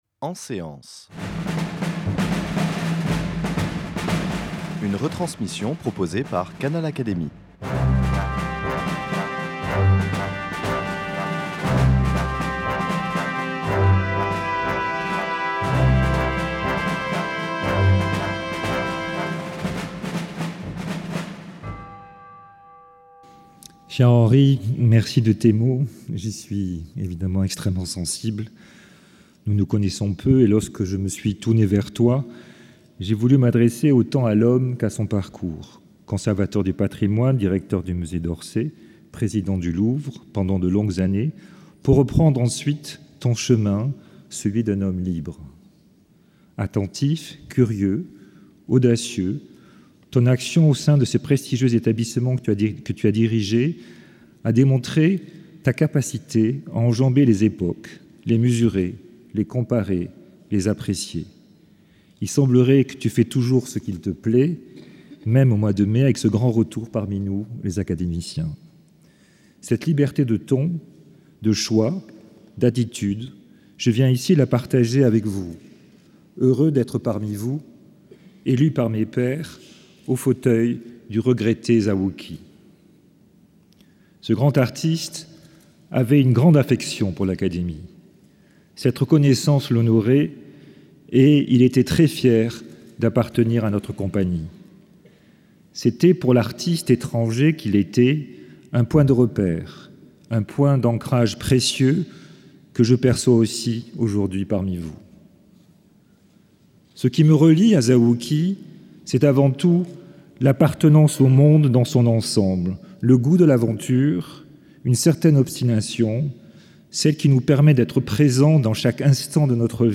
Cérémonie d’installation de Jean-Marc Bustamante à l’Académie des beaux-arts.
Au cours de cette cérémonie sous la Coupole de l’Institut de France, Henri Loyrette a prononcé le discours d’installation de Jean-Marc Bustamante avant d’inviter ce dernier à faire, selon l’usage, l’éloge de son prédécesseur, le peintre Zao Wou-Ki décédé le 9 avril 2013.